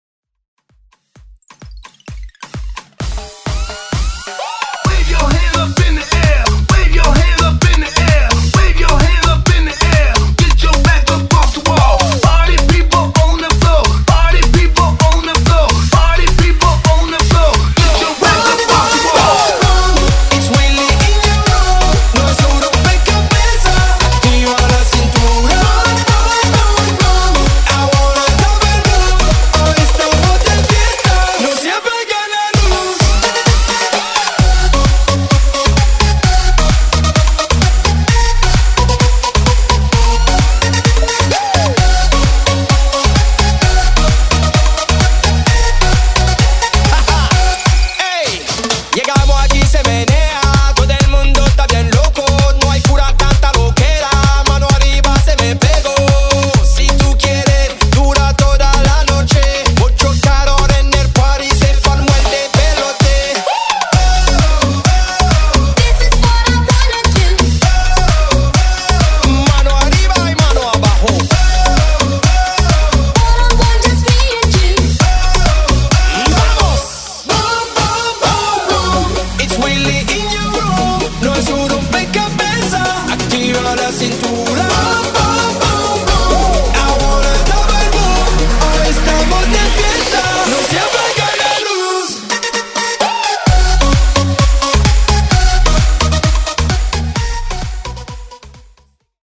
BPM: 130 Time